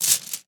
household
Plastic Tent Poles Dropping on Dirt